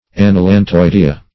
Search Result for " anallantoidea" : The Collaborative International Dictionary of English v.0.48: Anallantoidea \An`al*lan*toid"e*a\, n. pl.